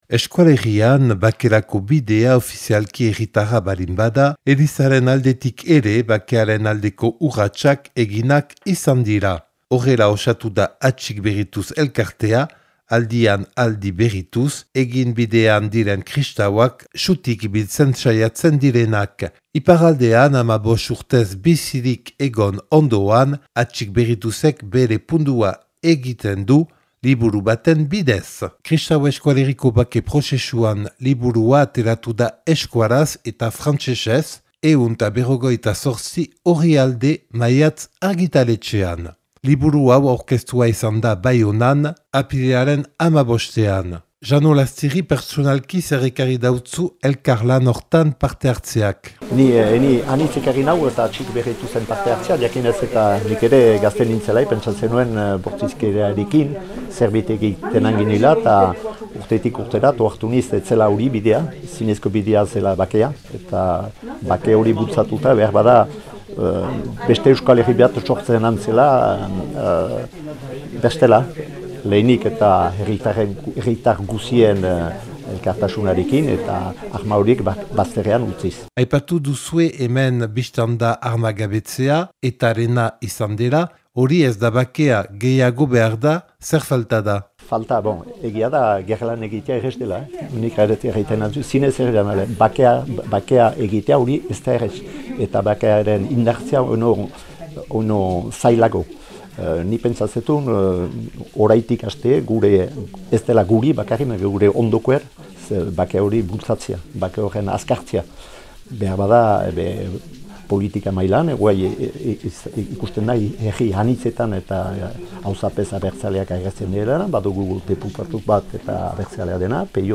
Maiatz argitaletxean argitaratu dute liburu hori, eta apirilaren 15ean aurkeztu zuten, Baionan, prentsaurreko batean.